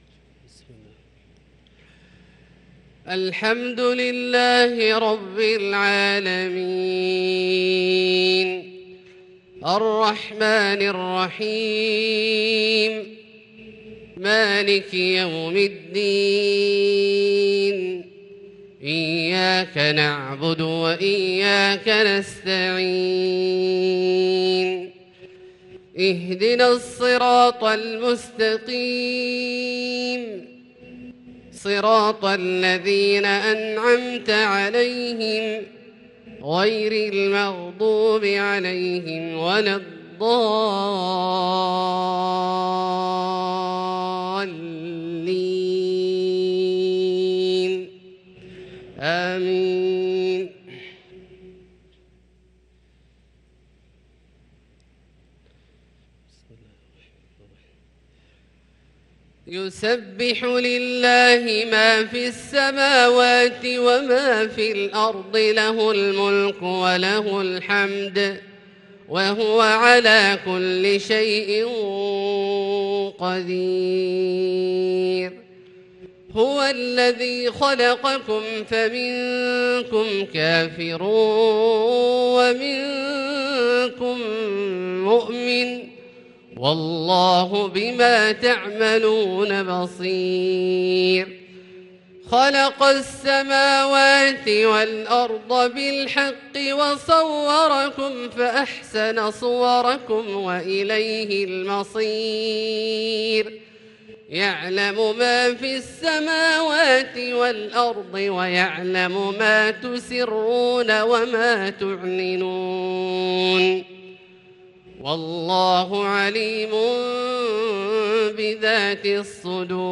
صلاة الفجر للقارئ عبدالله الجهني 5 ذو القعدة 1443 هـ